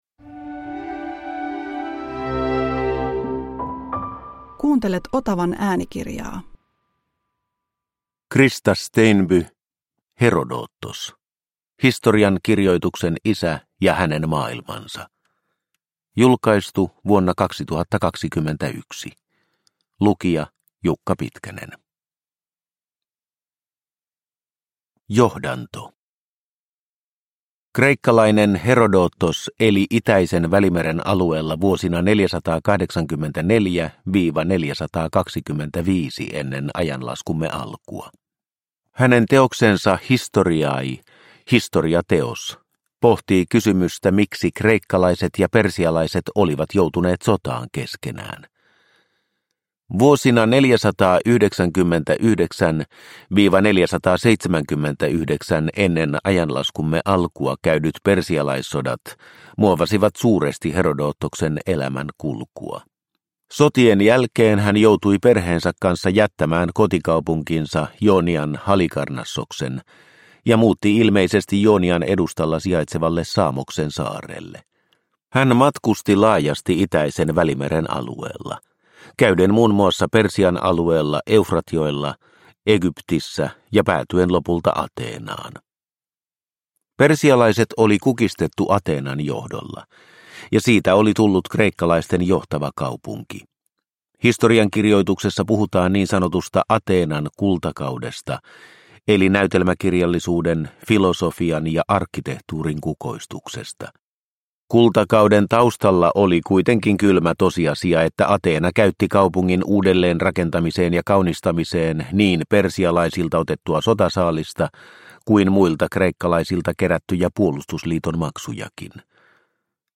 Herodotos – Ljudbok – Laddas ner